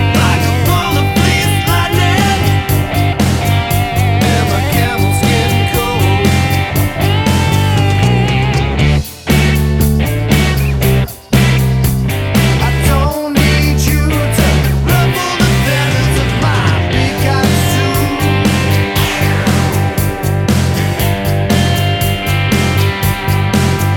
Minus Lead Guitar Pop (1990s) 3:10 Buy £1.50